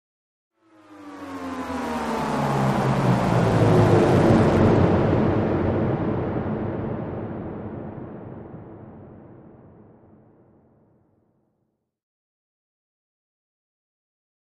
Sweep Electronic Feedback Descending Sweep with Reverb